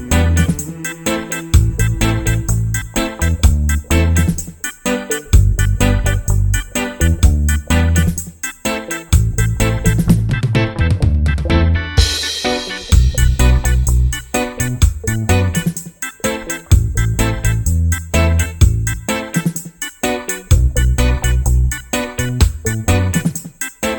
no Backing Vocals Reggae 3:44 Buy £1.50